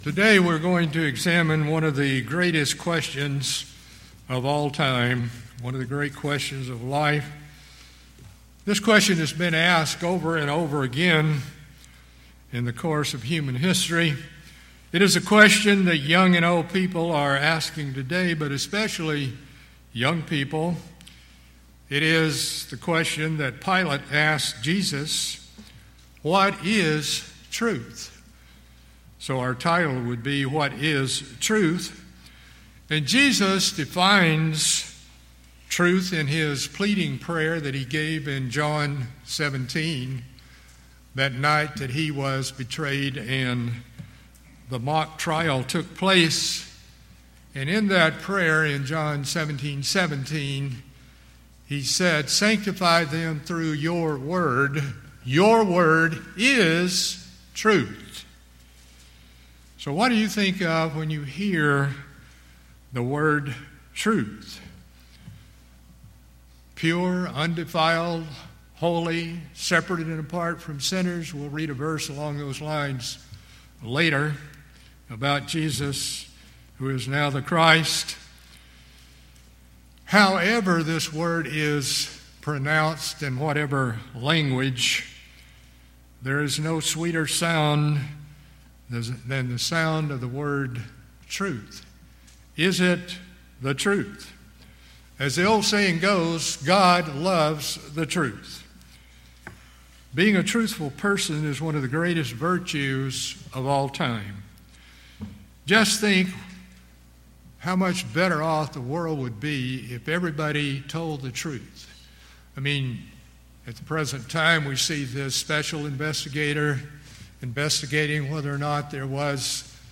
Answers to these questions are explored in this sermon.